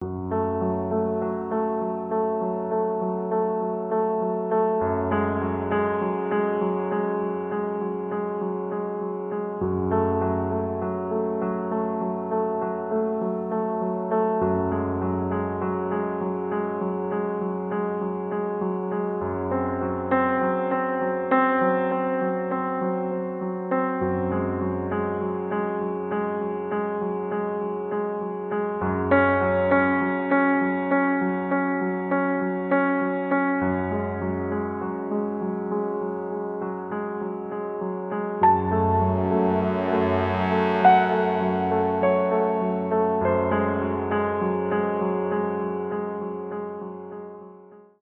грустные
печальные , инструментальные , пианино , без слов
жуткие